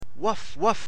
Ouaf — Landesbildungsserver Baden-Württemberg
Wau
ouah.mp3